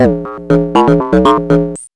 SYNTH_14__L.wav